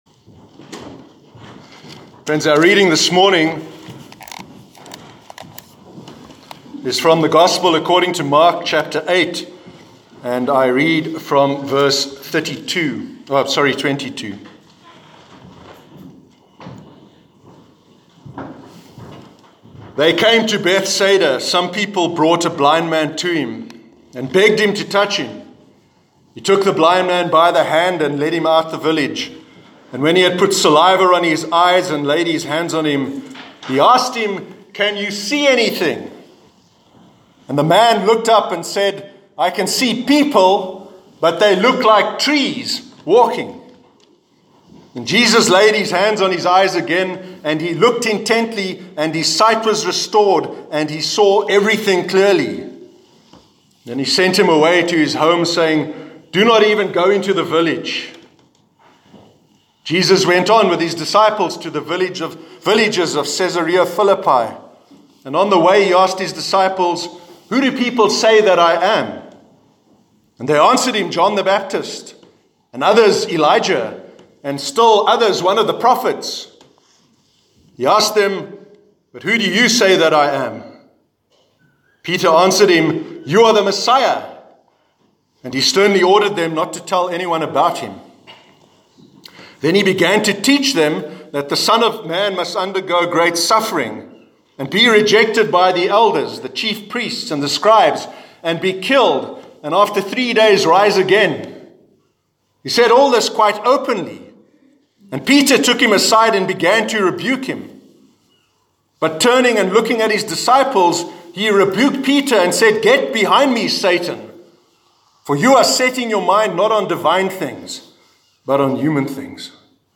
Reading: Mark 8:22-38